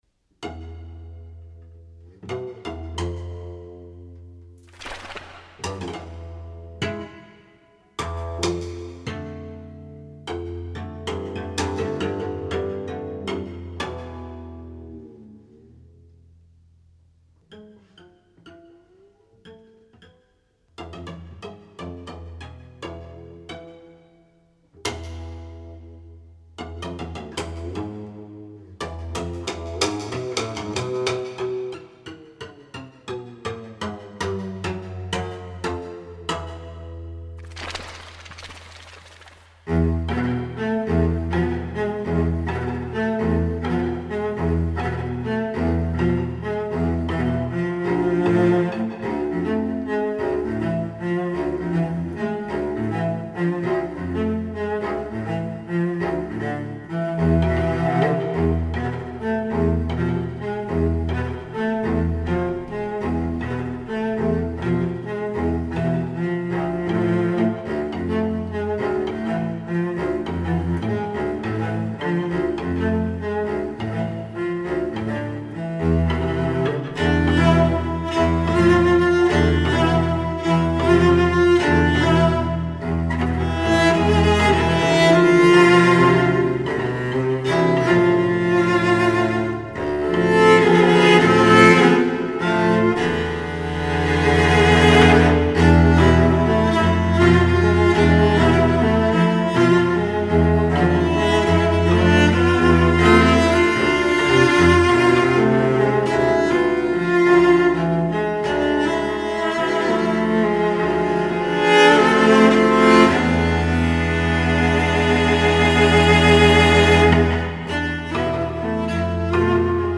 5 violoncelles
Alap improvisé